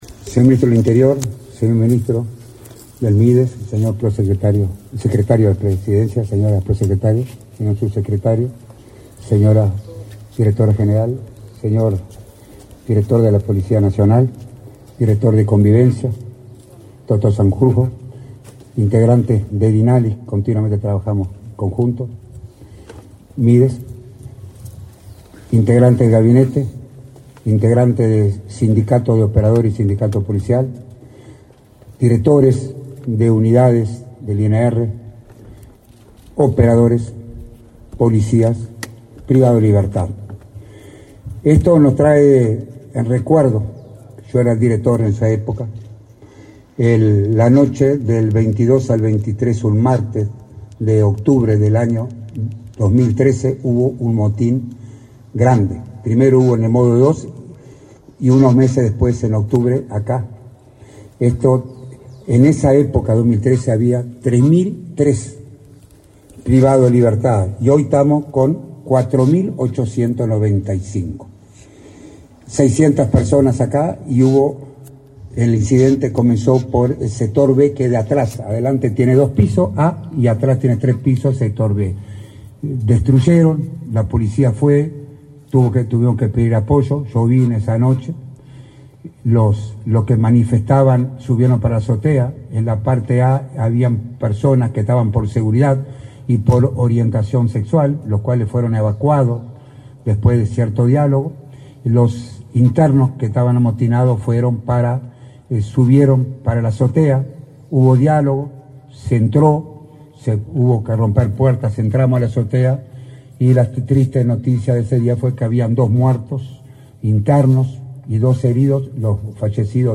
Acto de reinauguración del módulo I de la Unidad n.° 4 del INR
Con la participación del ministro del Interior, Nicolás Martinelli, y el director del Instituto Nacional de Rehabilitación (INR), Luis Mendoza, se